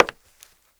HARDWOOD 3.WAV